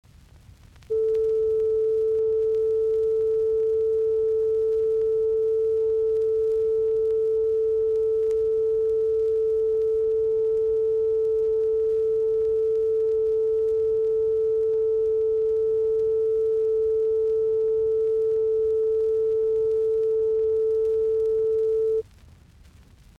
Reference A pitch for tuning [viritysääni, a1]